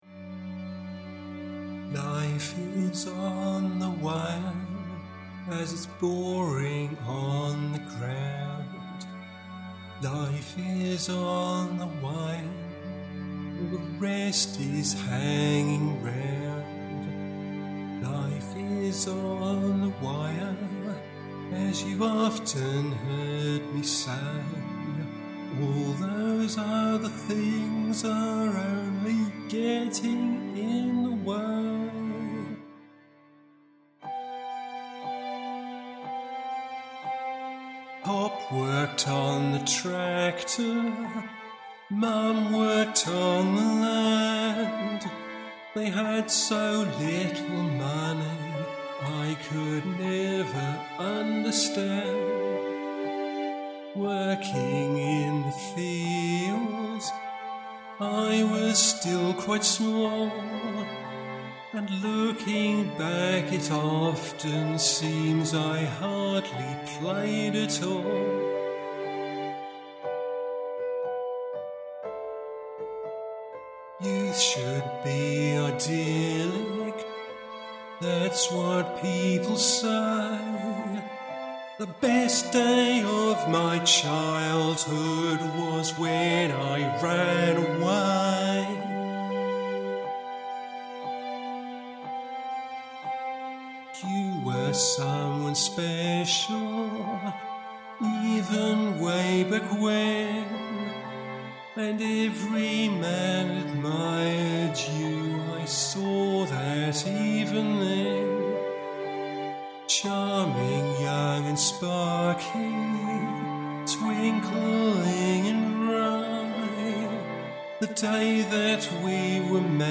Vocals recorded 27 September 2019.